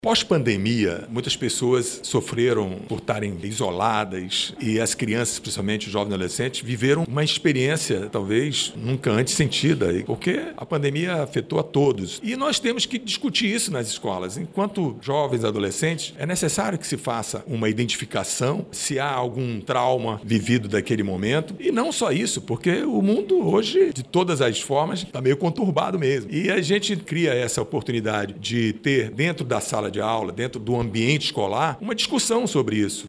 O autor do Projeto explica que a iniciativa é necessária, principalmente, após o período pandêmico, em que se intensificaram os casos de transtornos, entre crianças e adolescentes.